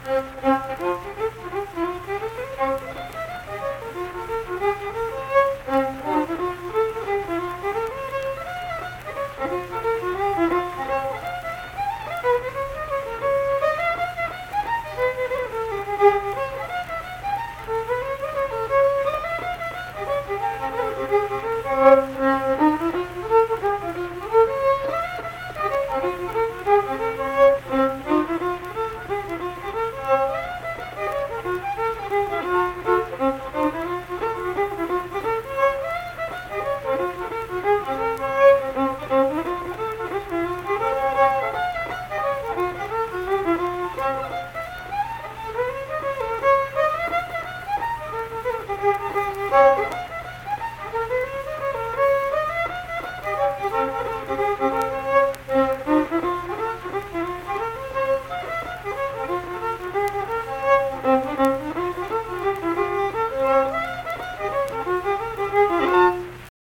Unaccompanied vocal and fiddle music
Instrumental Music
Fiddle